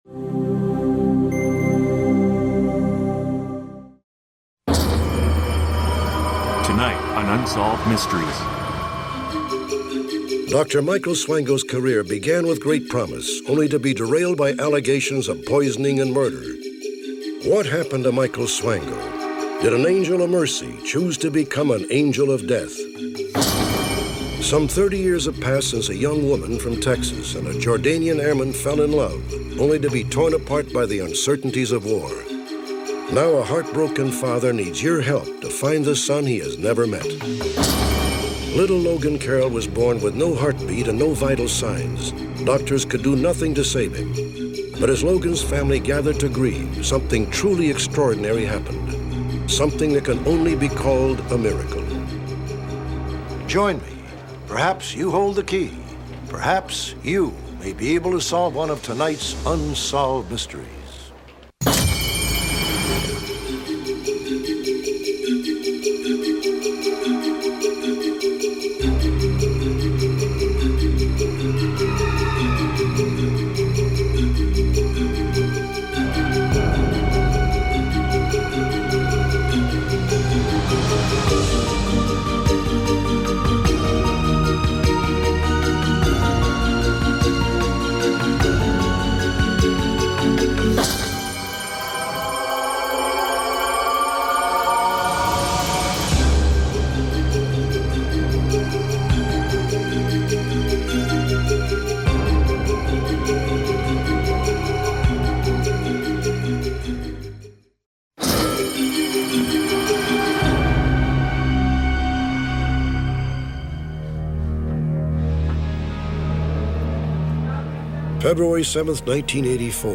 All advertisements are strategically placed only at the beginning of each episode, ensuring you can immerse yourself fully in every investigation, every revelation, and every emotional moment without advertising cuts disrupting the flow of our true crime storytelling.